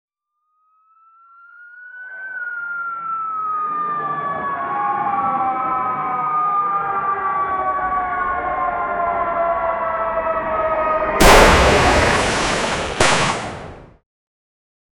the-ambience-of-gunshots--p77wyfl3.wav